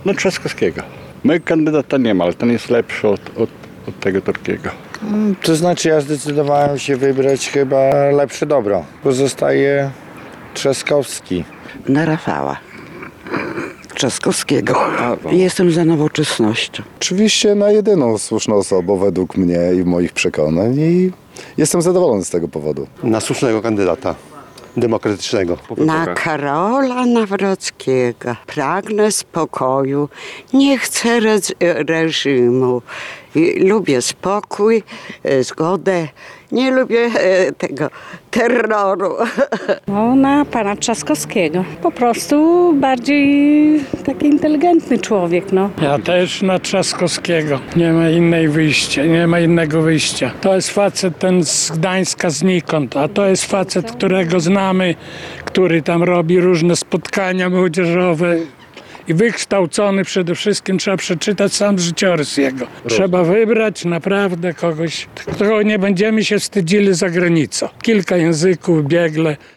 Rafała Trzaskowskiego najczęściej wymieniali wyborcy, których spotkaliśmy w niedzielę (01.06) w lokalach wyborczych.
Radio 5 tradycyjnie, w dniu wyborów od rana, z kamerą i mikrofonem przyglądało się głosowaniu.